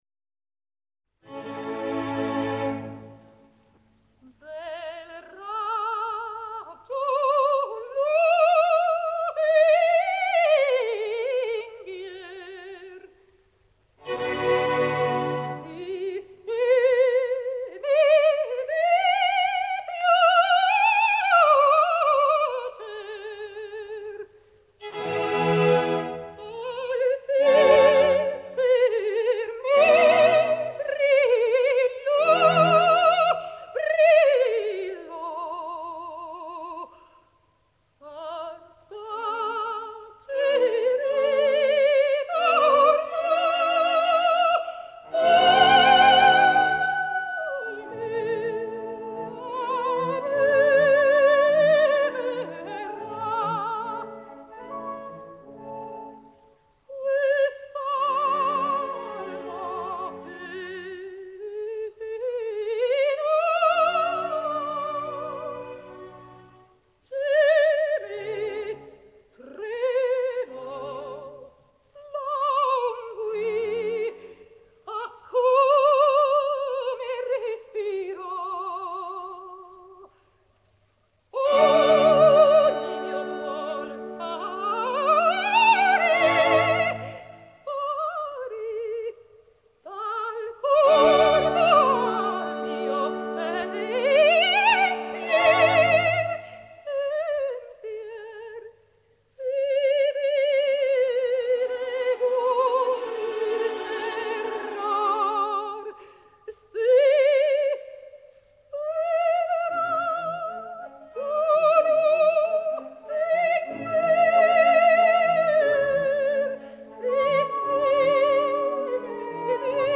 а фрагмент из оперы, с хором